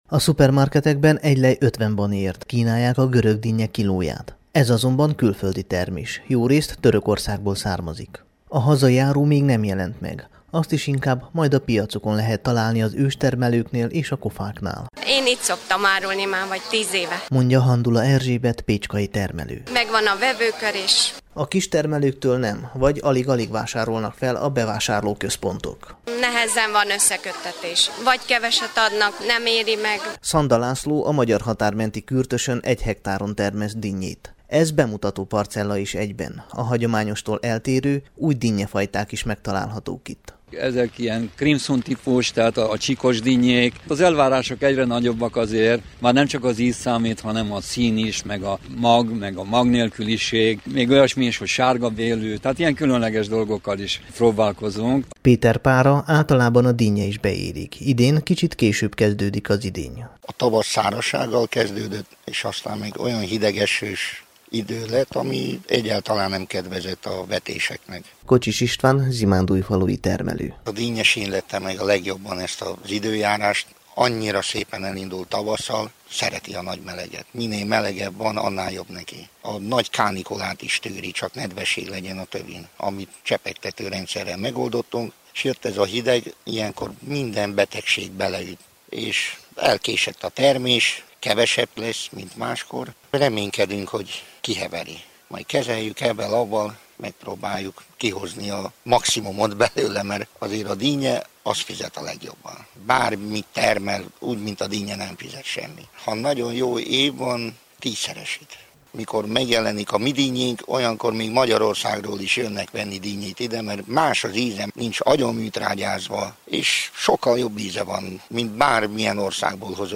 Arad megyei dinnyekörképe a Temesvári Rádióban.